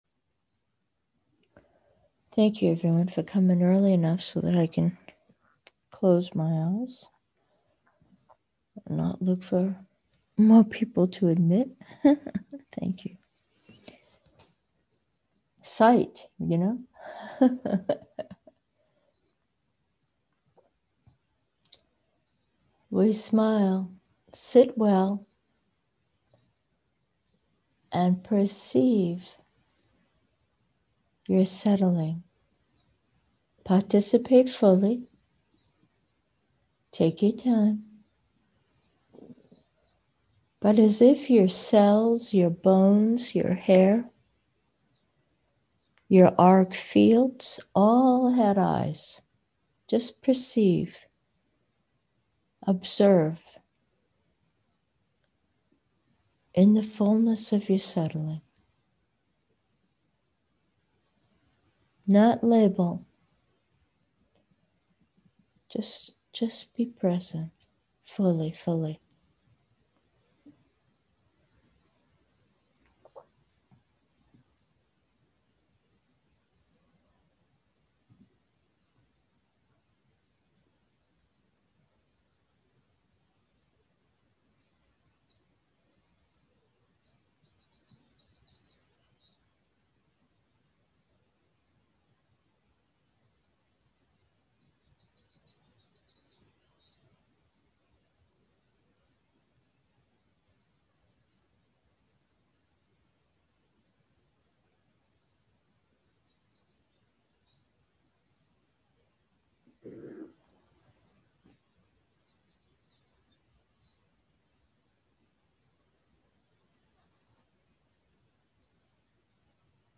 Meditation: awakened senses